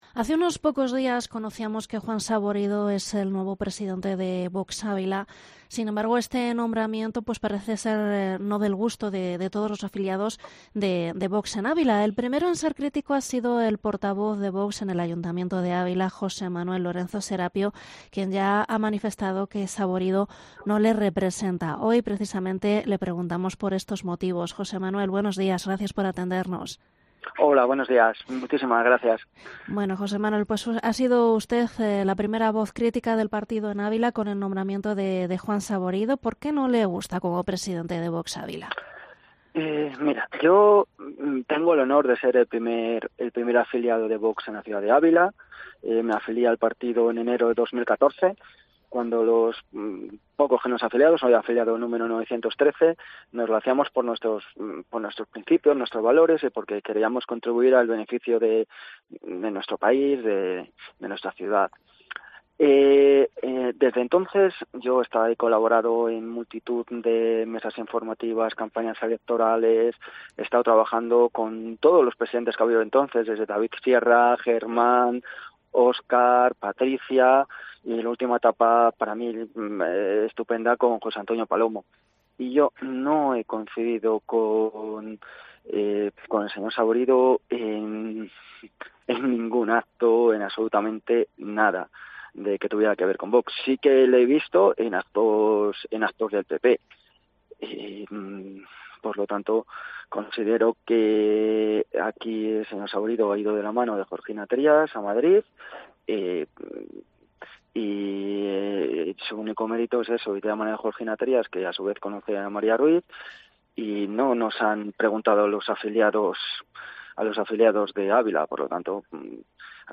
ENTREVISTA / José Manuel Lorenzo Serapio, portavoz Vox en el Ayuntamiento de Ávila